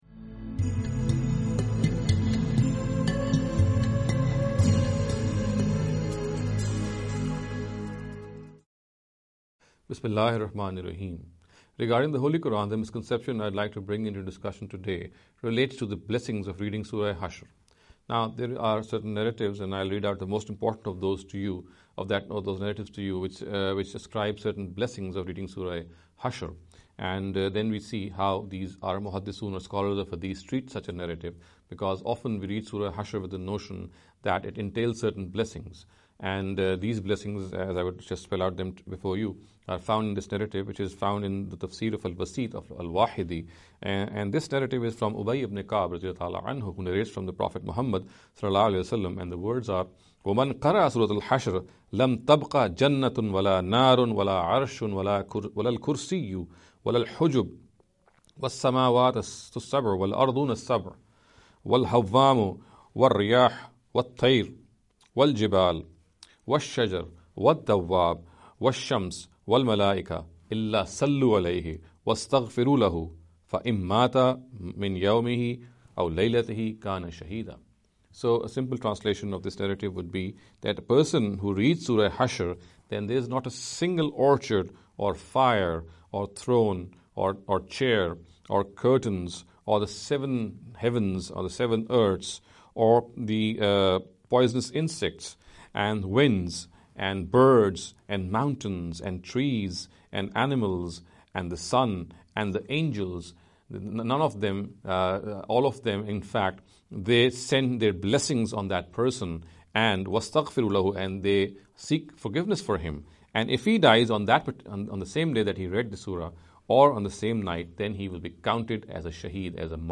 In every lecture he will be dealing with a question in a short and very concise manner.